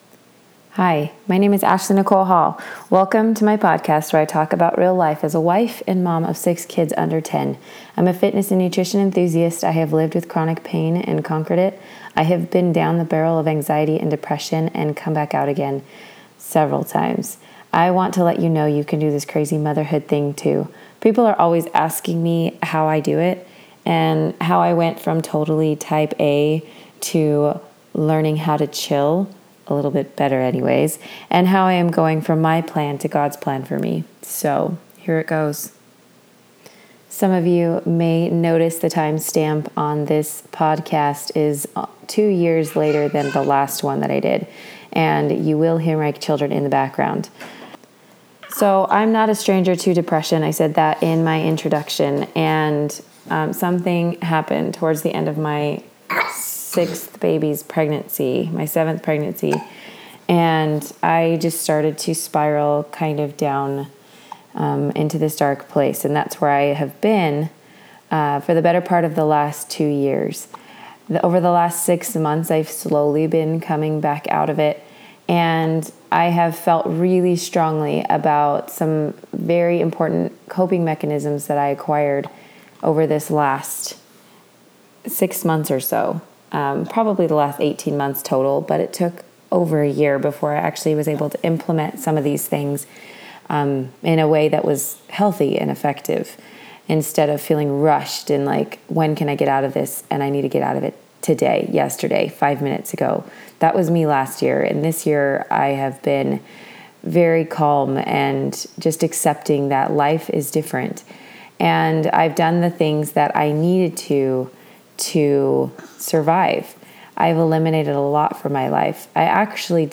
Podcast note: I DID NOT EDIT my podcast.
So enjoy the ‘ums’ and my 3 yr old hanging out with me in the background.